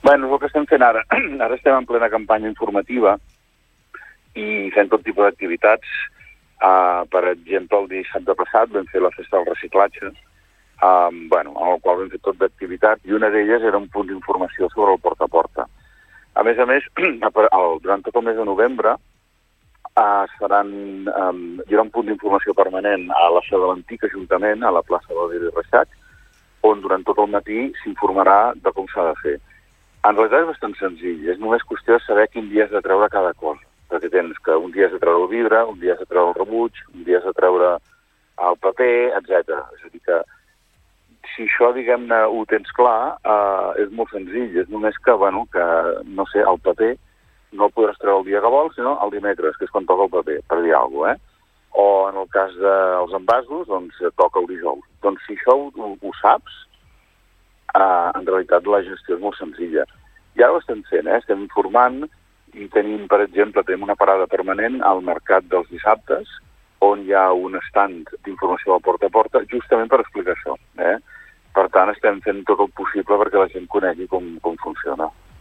Entrevistes SupermatíNotícies
Per entendre com funcionarà aquest servei ens ha visitat al Supermatí el regidor de medi ambient del consistori de Santa Cristina d’Aro, David Segarra.